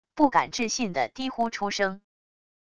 不敢置信的低呼出声wav音频